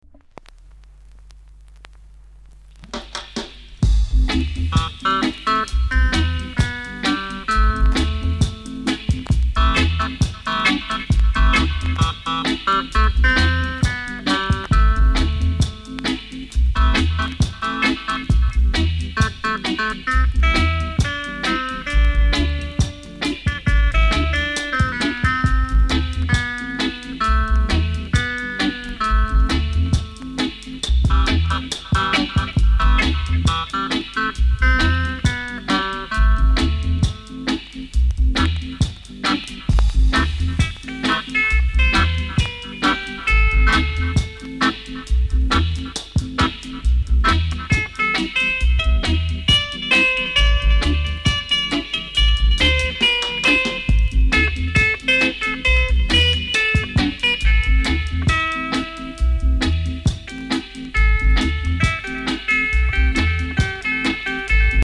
※チリ、パチノイズが少しあります。